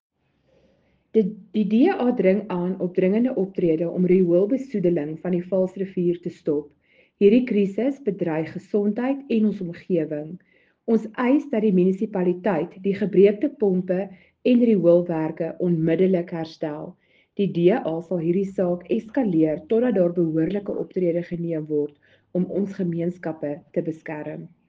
Afrikaans soundbites by Cllr Marelize Boeije and